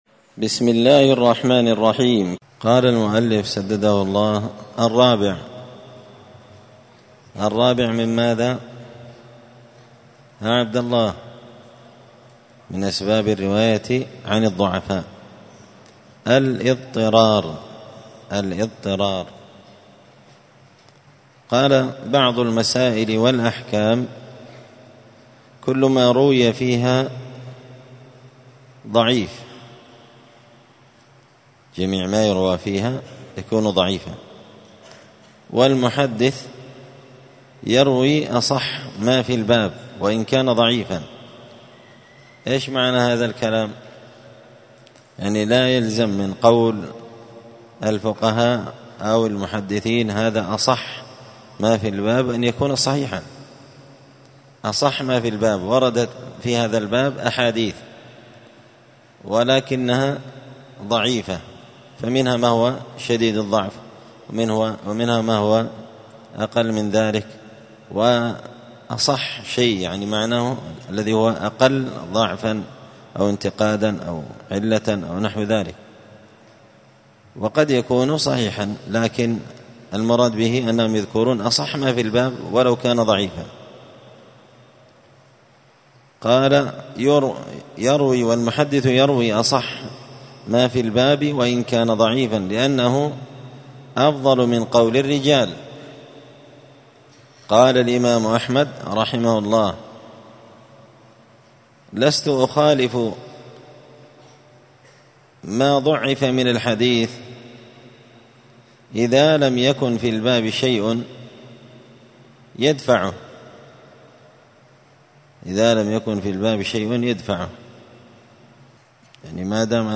*الدرس الثالث عشر (13) من أسباب الرواية عن الضعفاء*